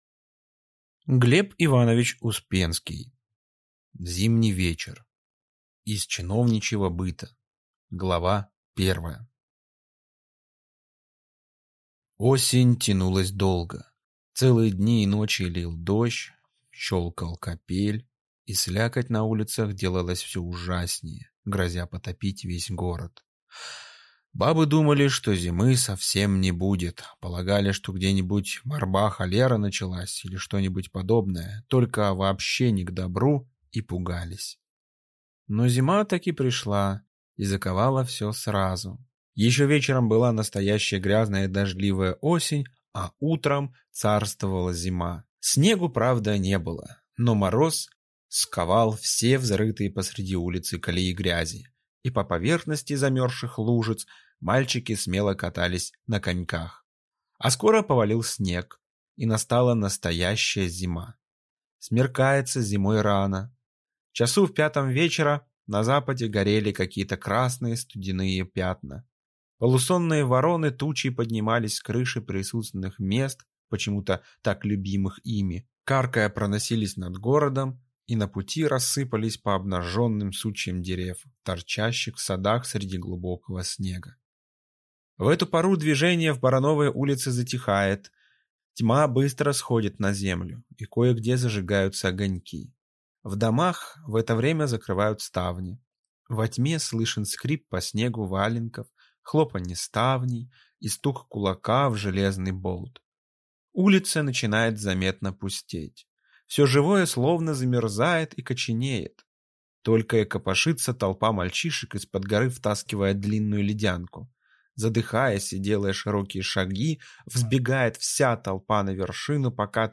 Аудиокнига Зимний вечер | Библиотека аудиокниг